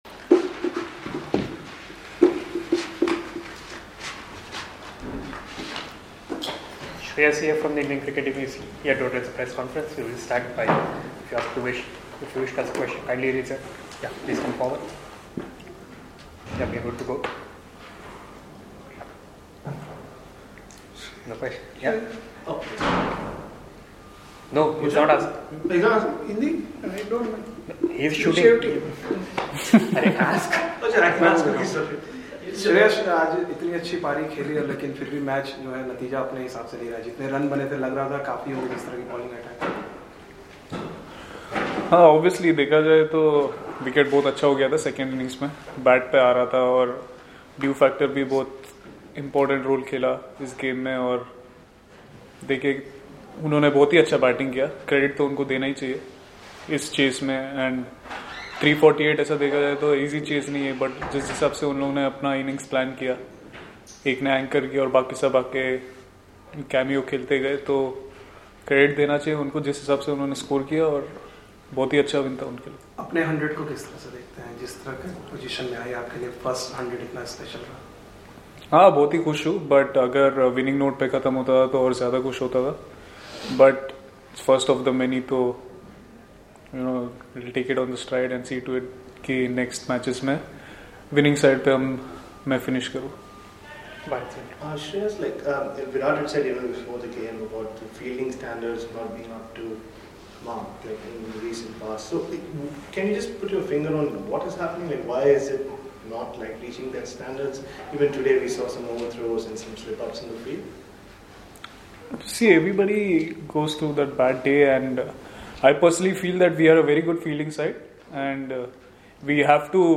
He spoke to the media in Hamilton on Wednesday after the first ODI against New Zealand.